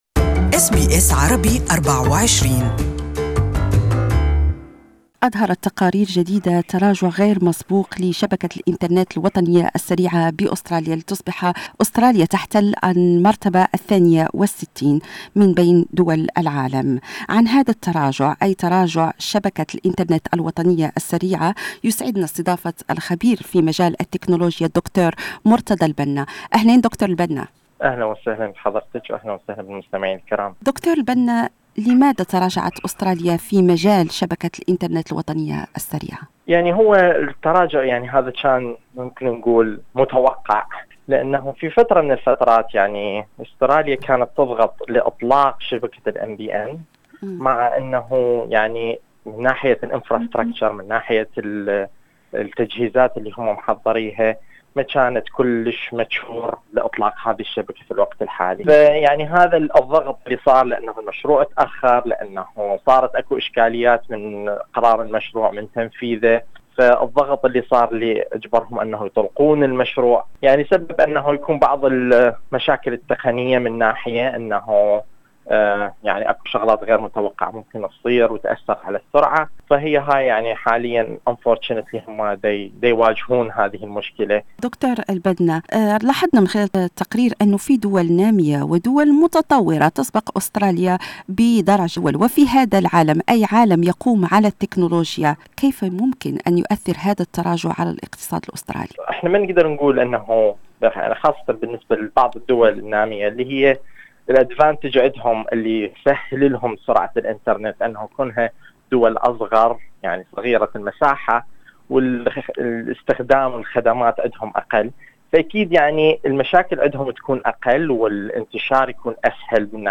استمعوا الى المزيد في المقابلة الصوتية أعلى الصفحة.